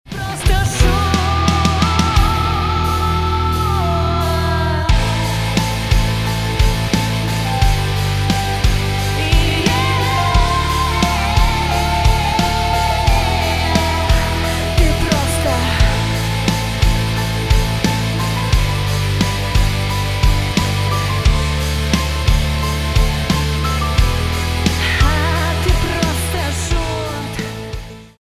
поп
красивые
женский вокал
барабан